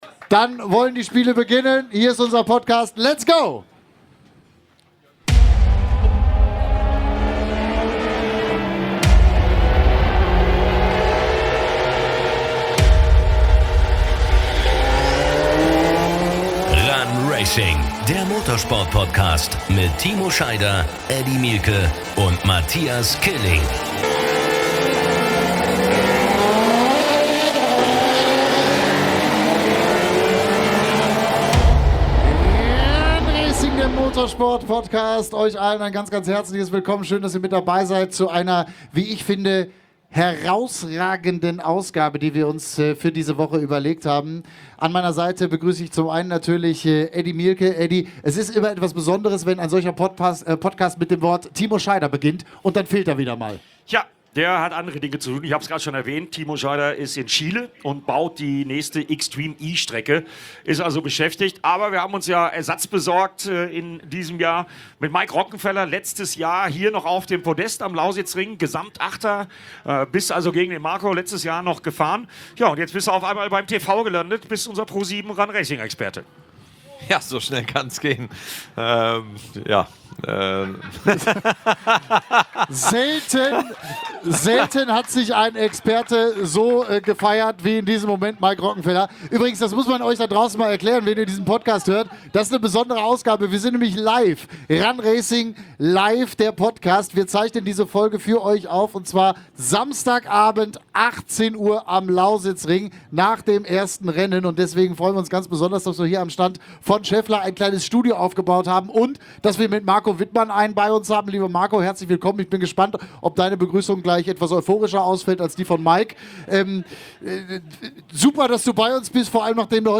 Die Folge wurde vor Publikum aufgezeichnet und Ihr erfahrt spannende Insights aus dem Leben zweier Motorsport-Grössen. Es wird gequatscht, gelacht und analysiert.